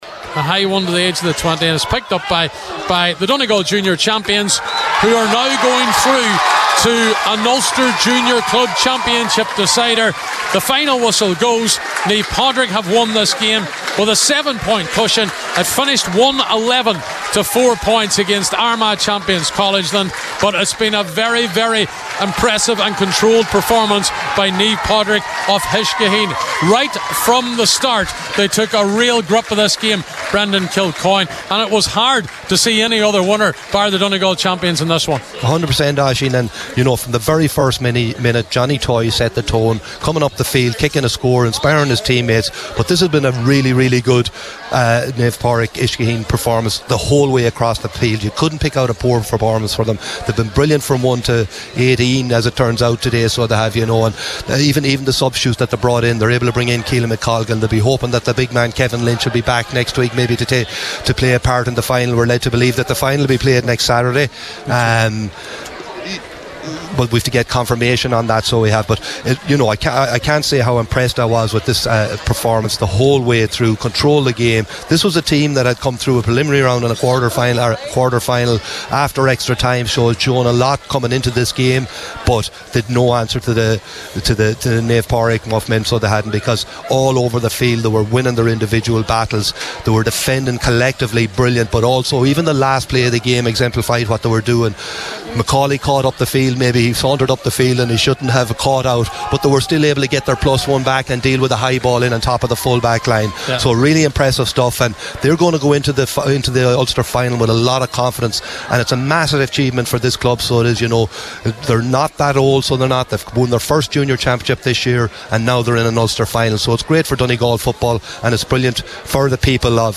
With the full time report